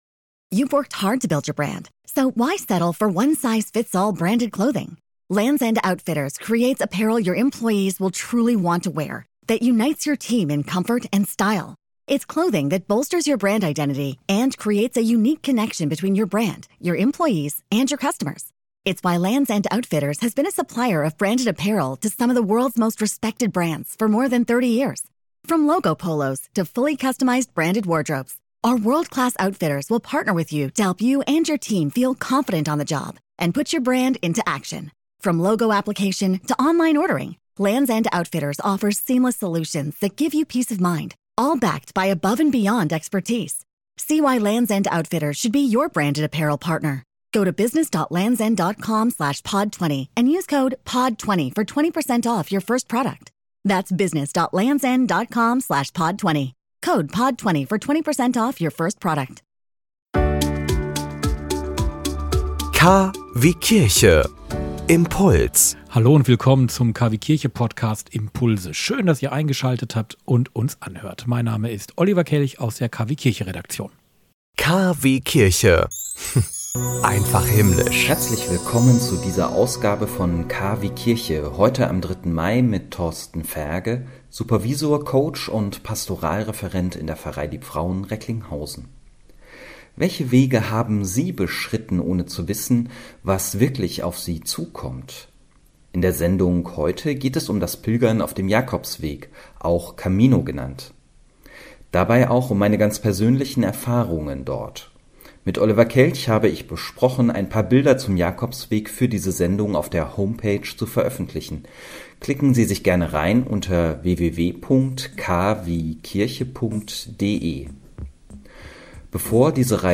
Dieser KwieKIRCHE-Impuls lief am 3. Mai 2020 bei Radio Vest (Kreis Recklinghausen).